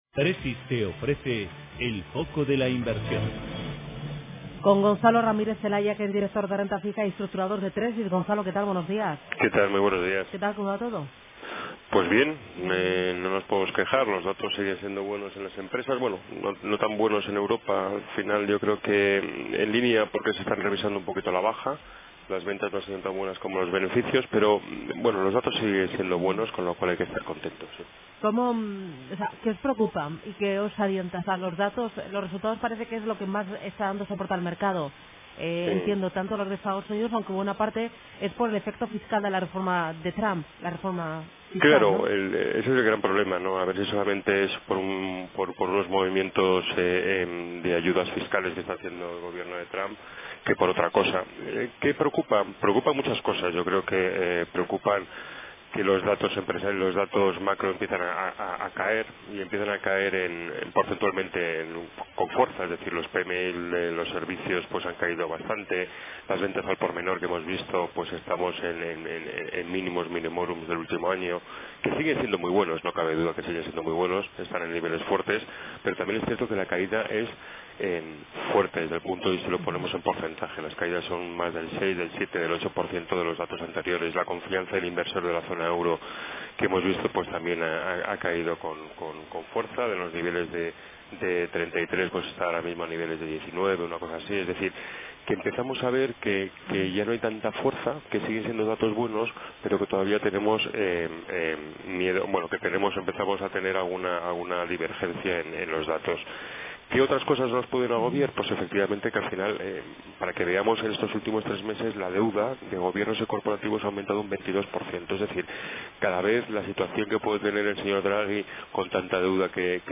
En Radio Intereconomía todas las mañanas nuestros expertos analizan la actualidad de los mercados.